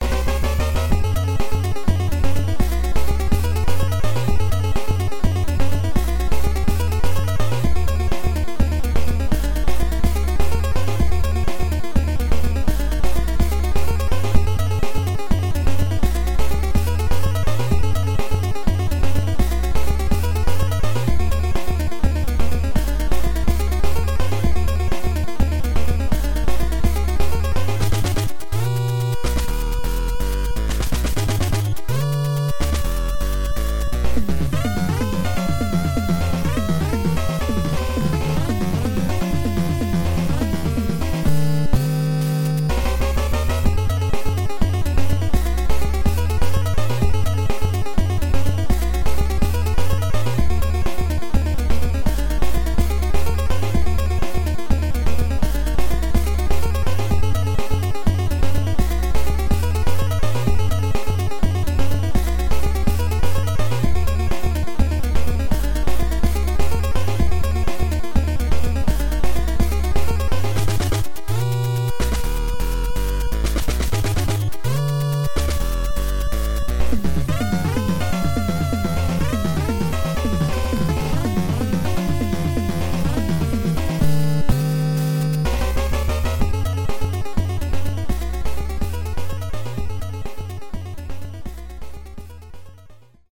Dendy Game Music